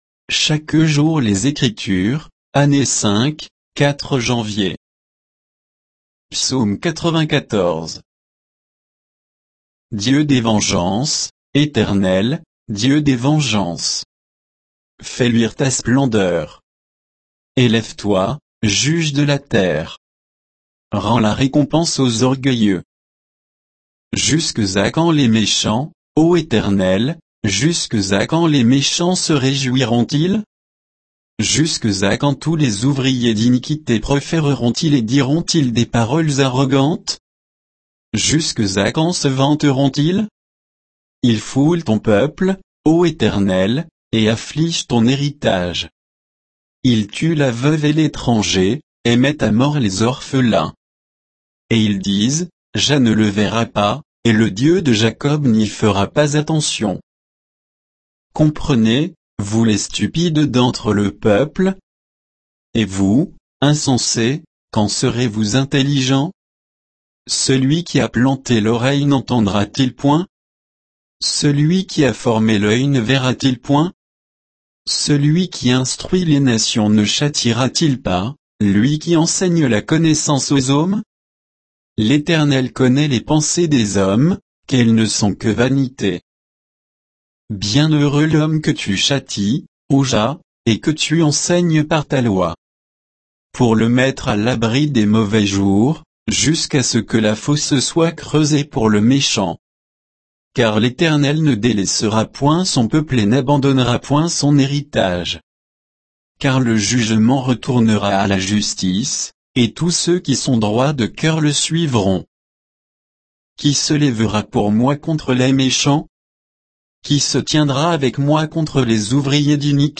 Méditation quoditienne de Chaque jour les Écritures sur Psaume 94